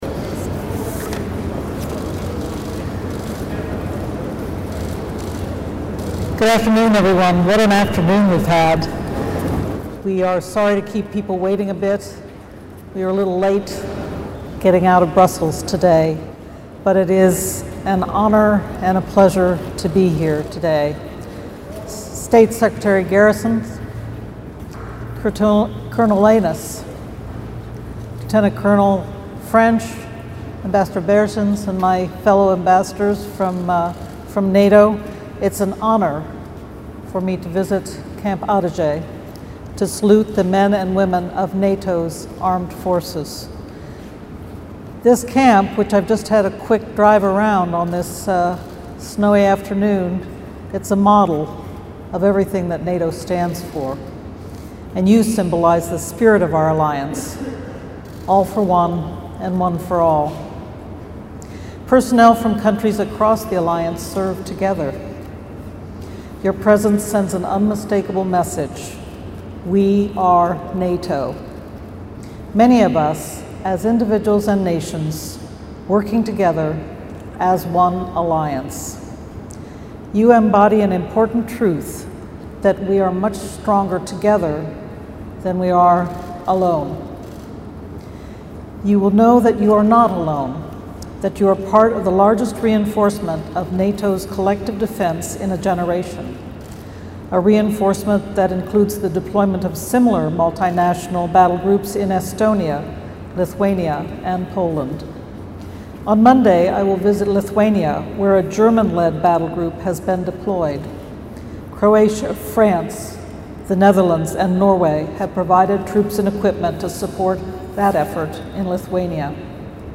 ENGLISH - Lecture by NATO Deputy Secretary General Rose Gottemoeller at Riga Graduate School of Law 02 Feb. 2018 | download mp3 ENGLISH - Remarks by NATO Deputy Secretary General Rose Gottemoeller at NATO's enhanced Forward Presence (eFP) battalion at Camp Adazi in Latvia 01 Feb. 2018 | download mp3